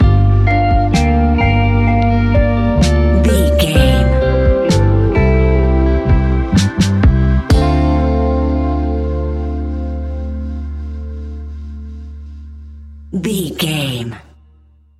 Ionian/Major
D
laid back
Lounge
sparse
chilled electronica
ambient
atmospheric